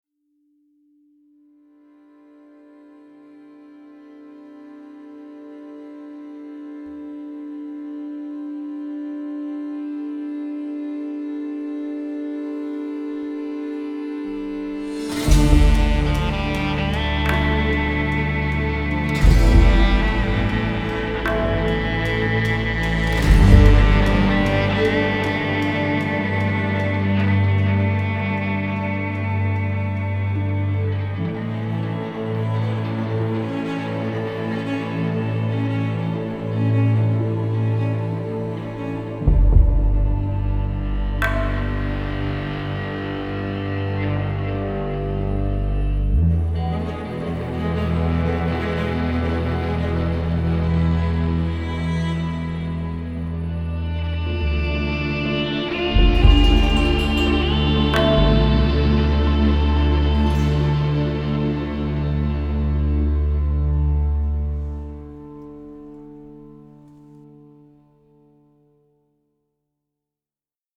Жанр: Score